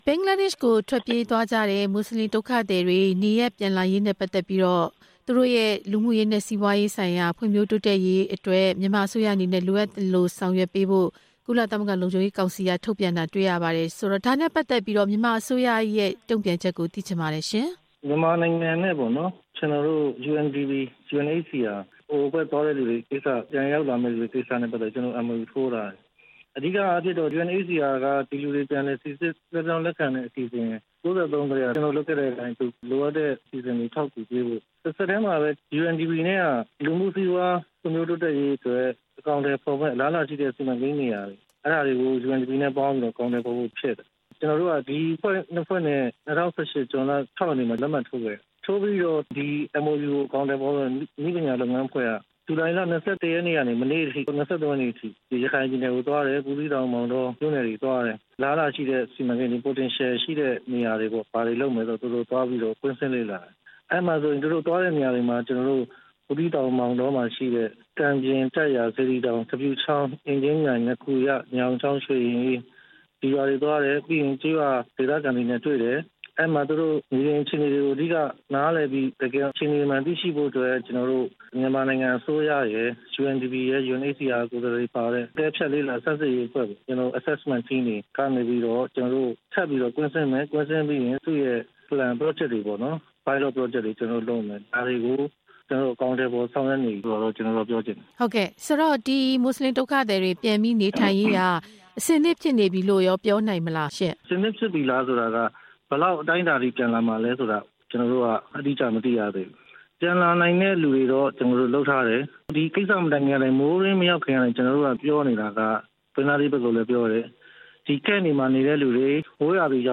ဘင်္ဂလားဒေ့ရှ်ရောက်ဒုက္ခသည်တွေ နေရပ်ပြန်ရေး ဆက်သွယ်မေးမြန်းချက်
မေးမြန်းခန်း